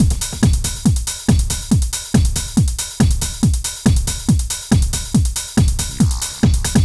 NRG 4 On The Floor 031.wav